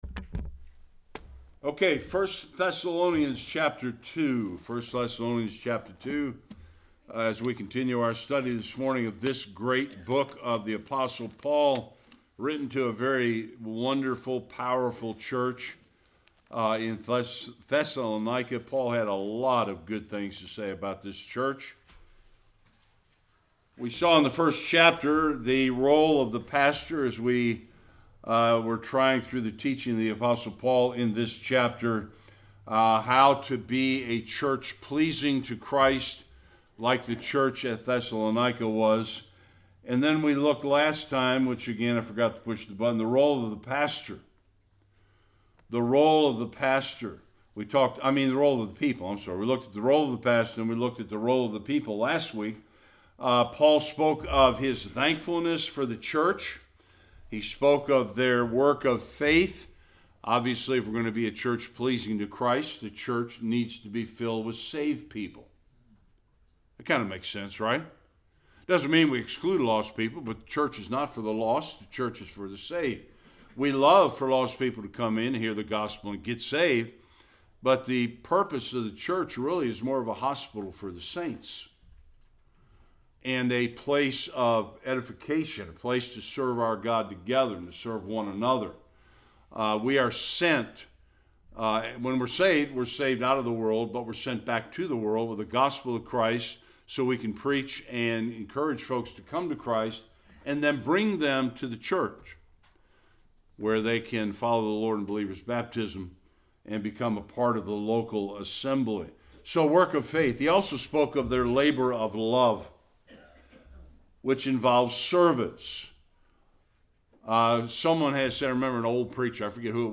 1-12 Service Type: Sunday Worship Notice Paul’s approach to the Gospel in this passage.